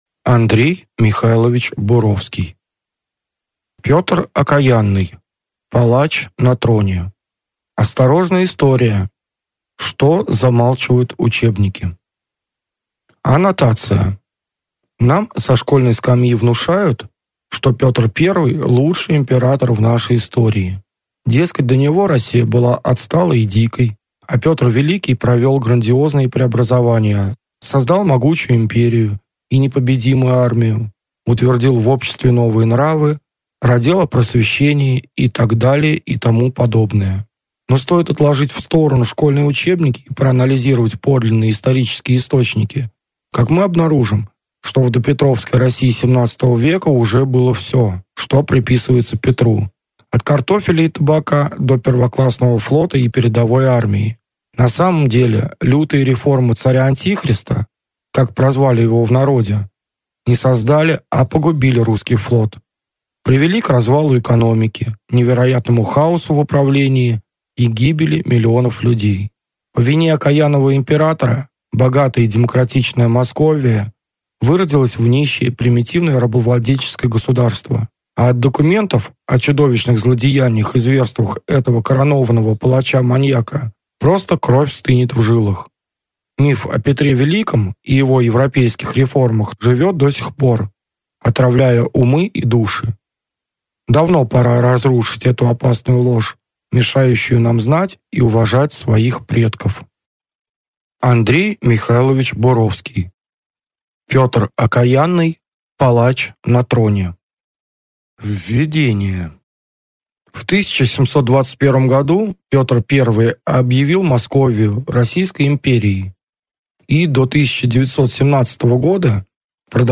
Аудиокнига Петр Окаянный. Палач на троне | Библиотека аудиокниг